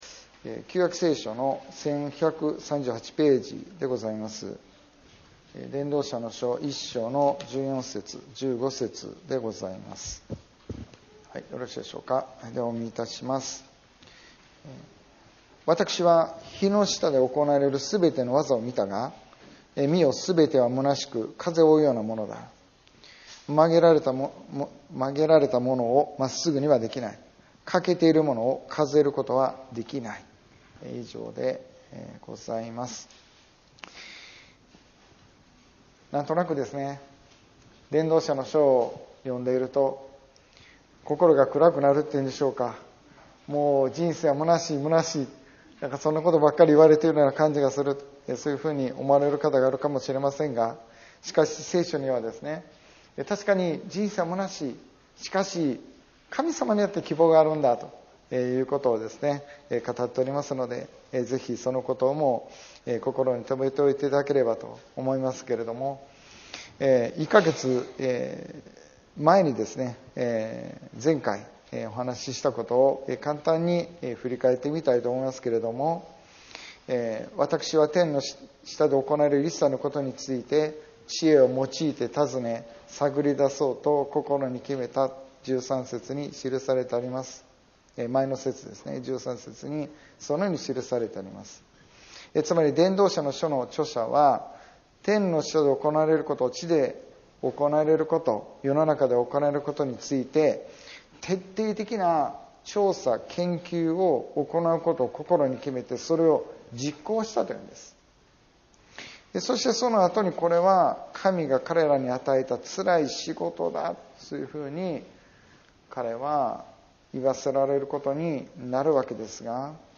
2025年10月26日礼拝説教「空しさからの解放」